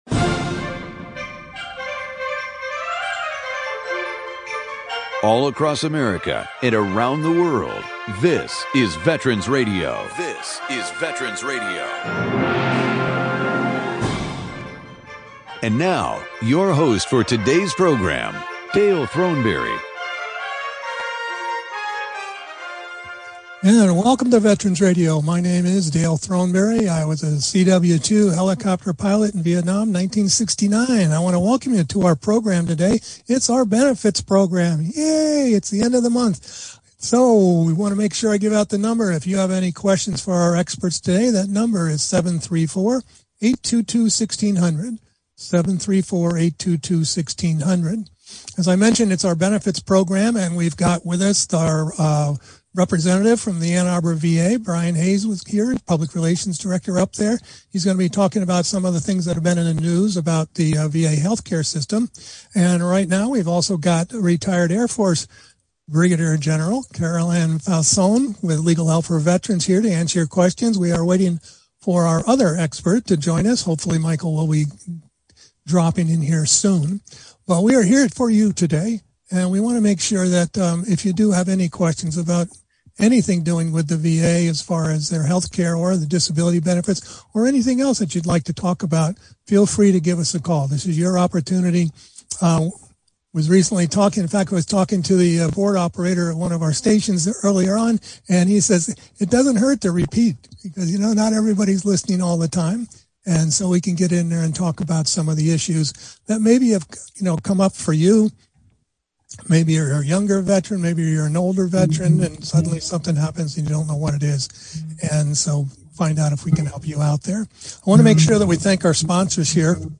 Monthly veterans benefits hour with our panel of experts.